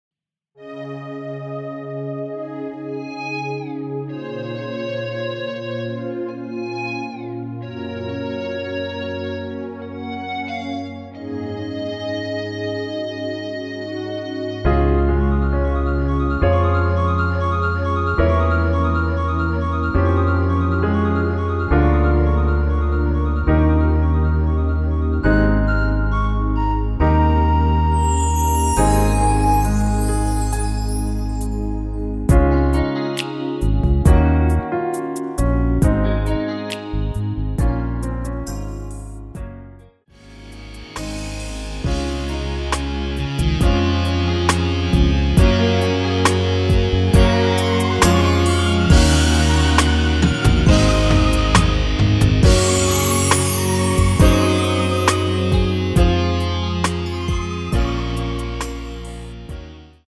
원키에서(+1)올린 MR입니다.
Db
앞부분30초, 뒷부분30초씩 편집해서 올려 드리고 있습니다.
중간에 음이 끈어지고 다시 나오는 이유는